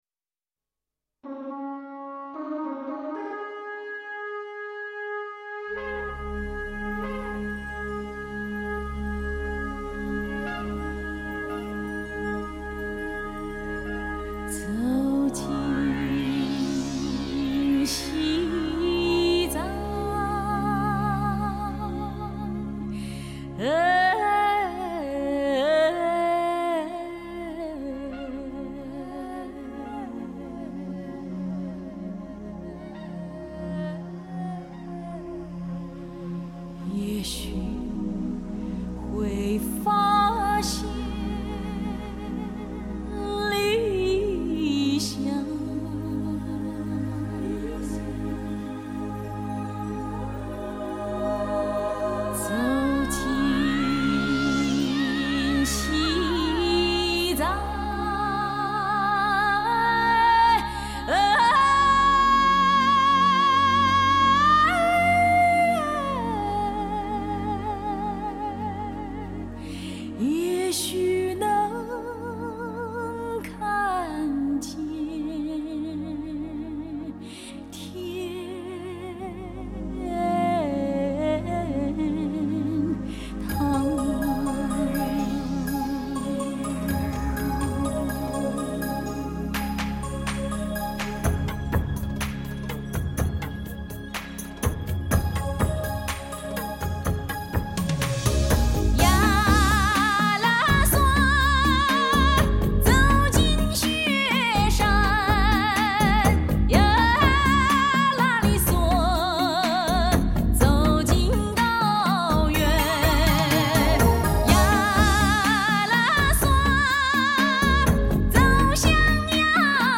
版本:WAV格式 24bit/96khz  2.0CH
音乐风格: 世界